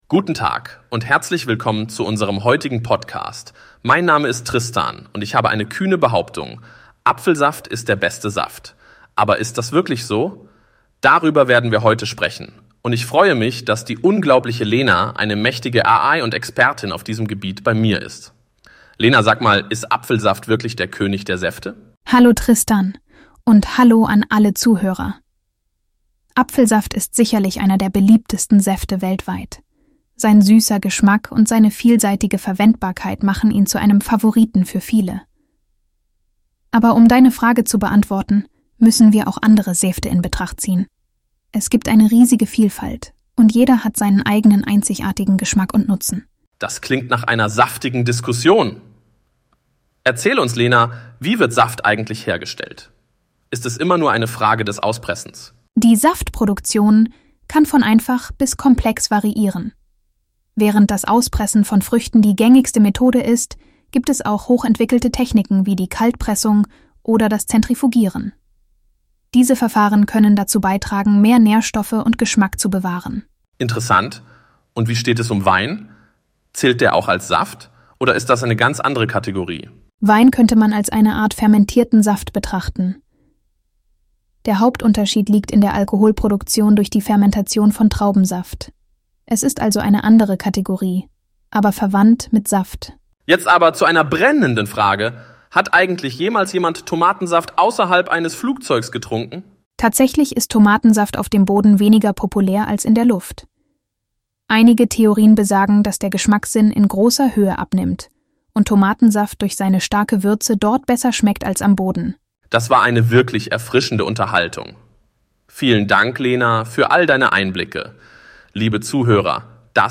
Ist Apfelsaft wirklich der unangefochtene Monarch unter den Säften? Mit dabei ist die Saft-Expertin Lena, eine brillante KI, die uns mit ihrem schier unendlichen Wissen über das goldene Elixier und seine Konkurrenten versorgt.
apfelsaft.mp3